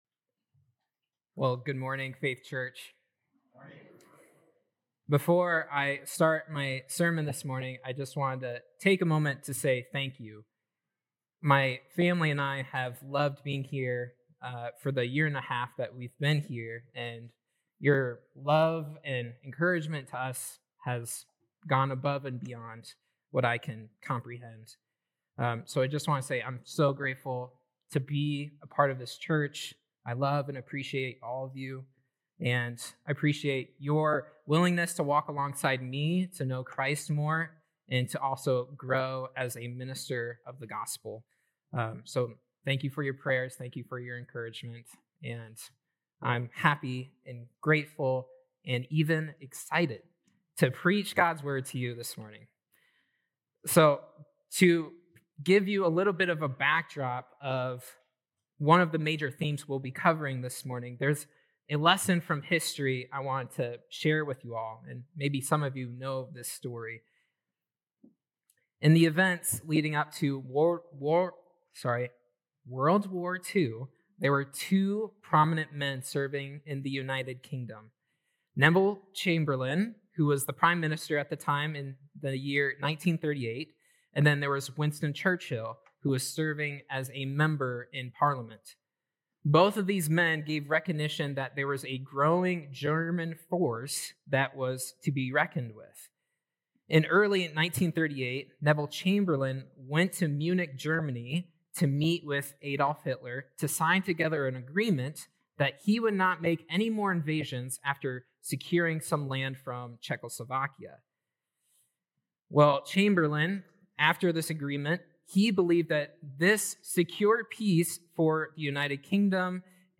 Dec 28th Sermon